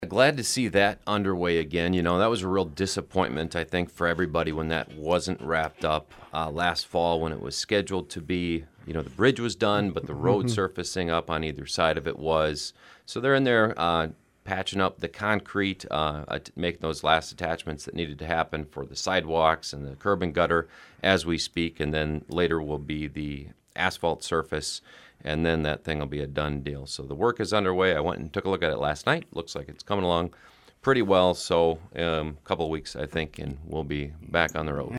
On Wednesday he appeared on the KSDN Midday Report to talk more about that.